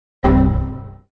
Windows XP Error Meme sound effects free download
Windows XP Error - Meme Sound Effect